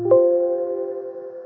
PCBeep.wav